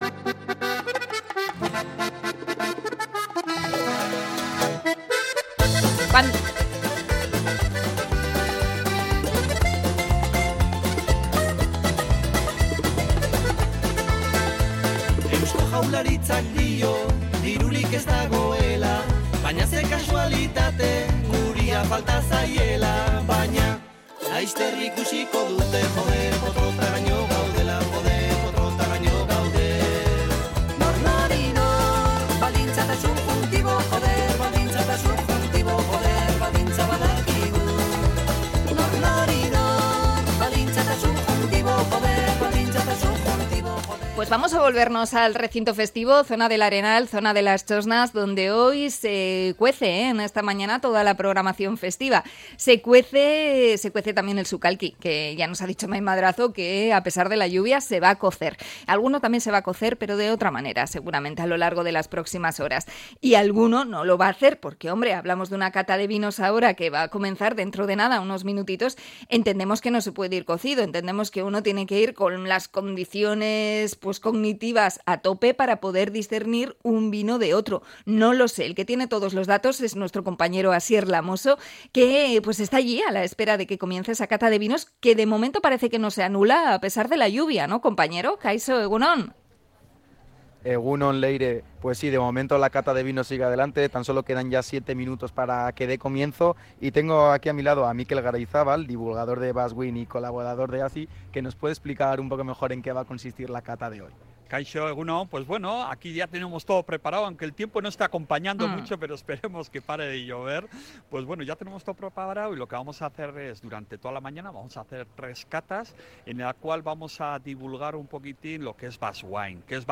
Conexión desde el Arenal con los organizadores de la cata de vinos